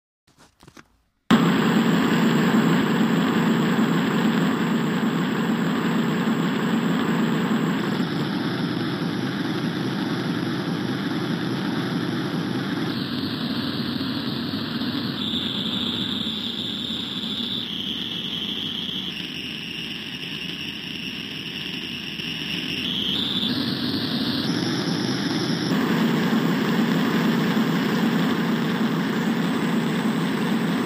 Soundgeräusch Dampflok